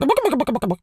turkey_ostrich_gobble_11.wav